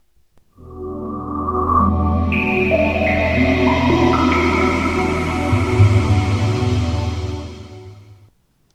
Sega Dreamcast Startup.wav